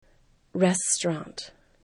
restaurant   stərɑnt